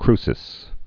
(krsĭs)